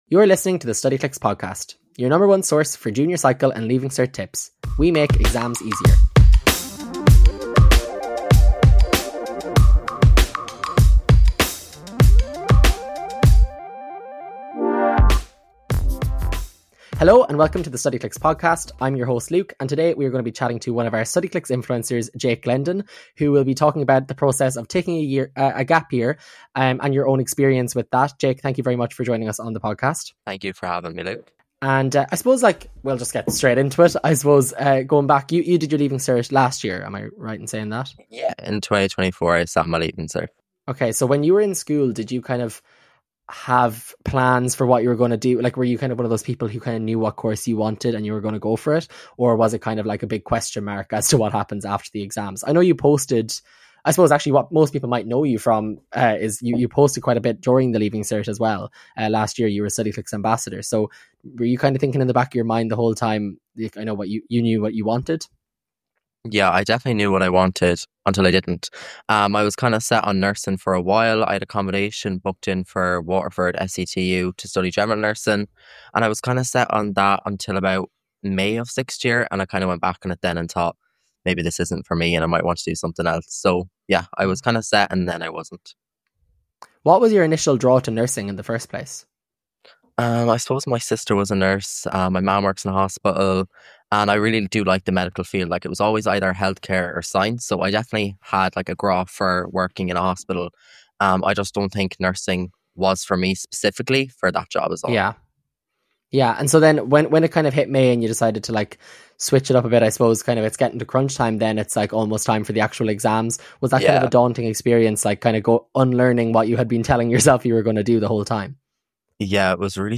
1 Musical Theatre in Cork School of Music (Interview with a college student) 28:32